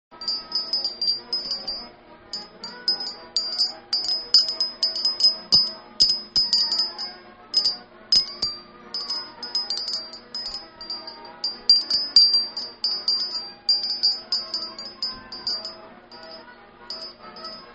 陶器製風鈴金魚染付